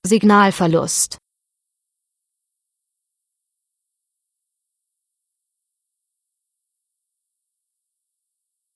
So habe mal eine 8 Sekunden Pause in den Soundfile eingefügt da nervt die Dame etwas weniger...